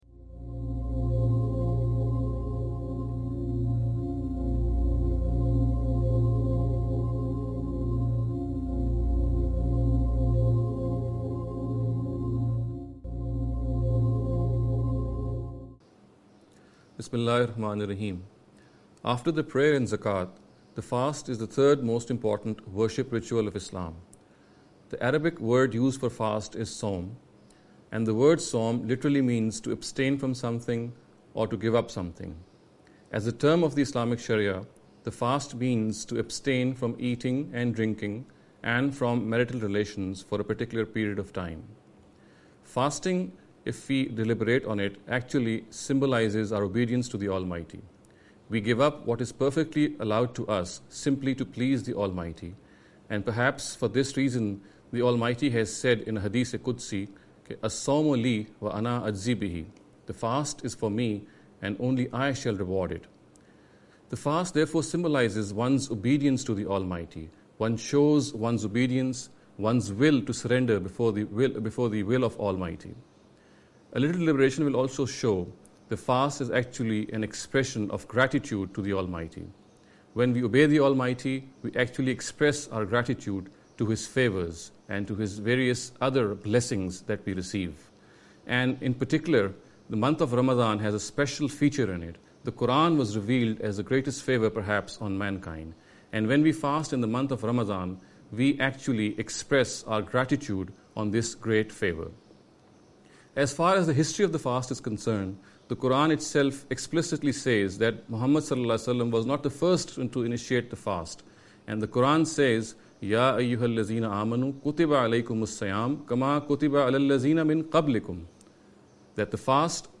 This lecture series will deal with some misconception regarding the Understanding The Qur’an. In every lecture he will be dealing with a question in a short and very concise manner. This sitting is an attempt to deal with the question 'The Fast’.